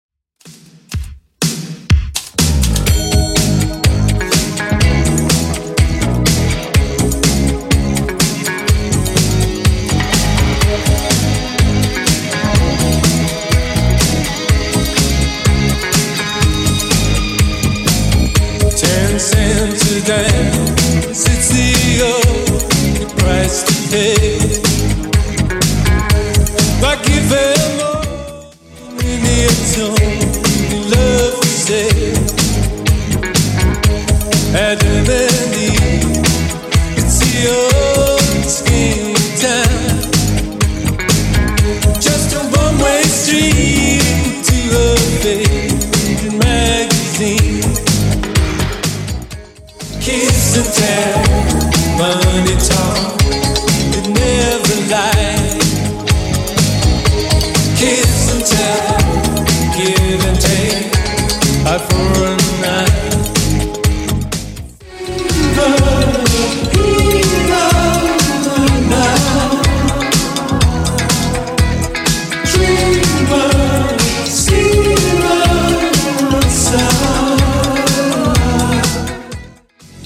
Genre: 80's
BPM: 125